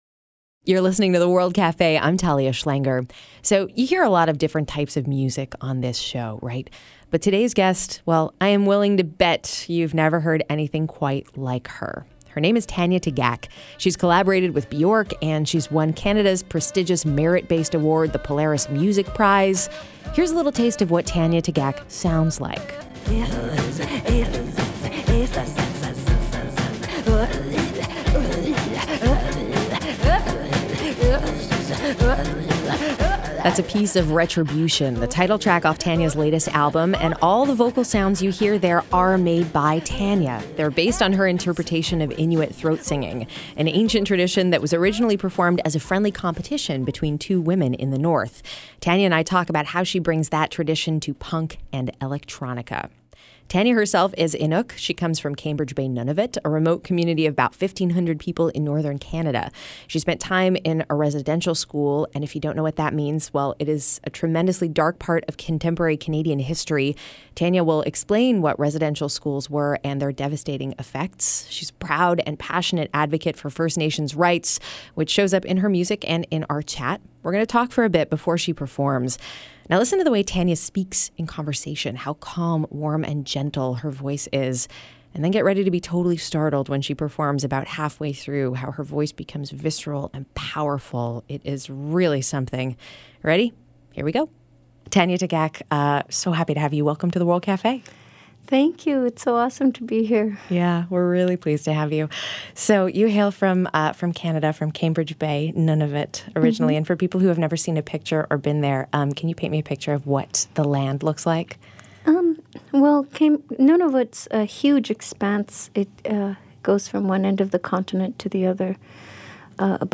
The breathtaking vocalist explains how she's brought her own interpretation of Inuit throat singing together with punk and electronica.